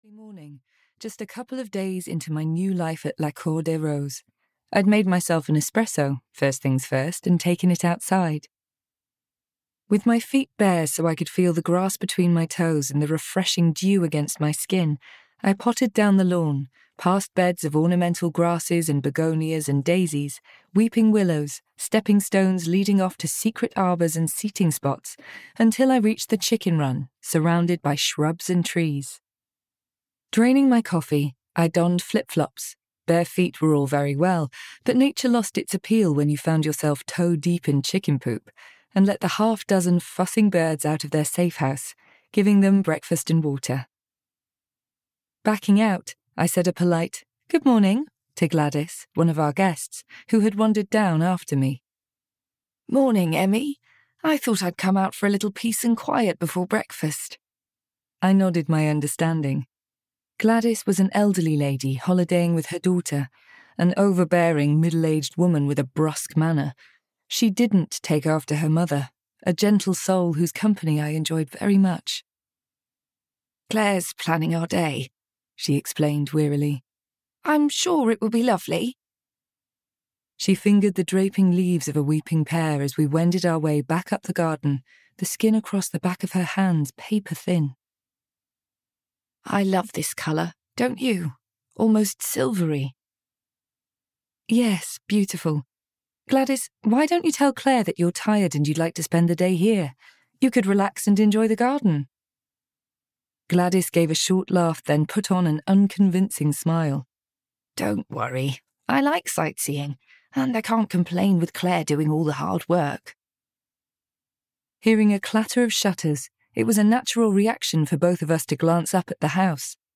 Return to the Little French Guesthouse (EN) audiokniha
Ukázka z knihy